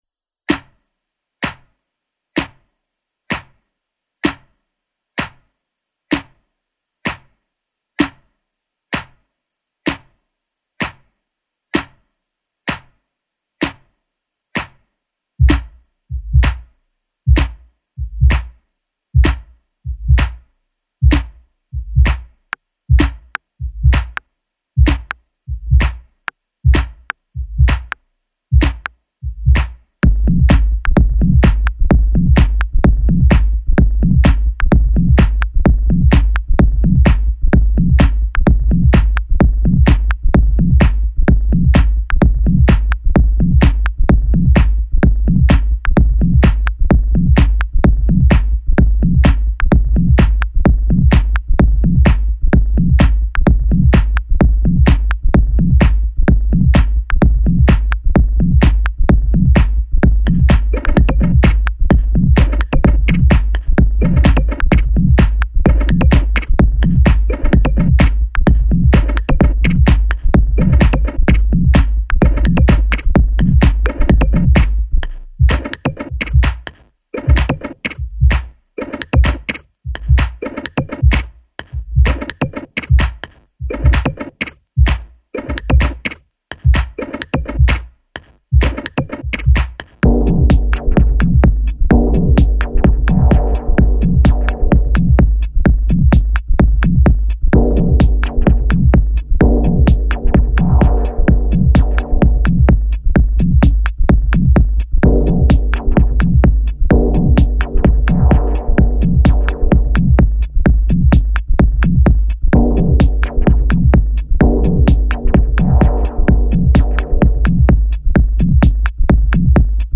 minimal / tech house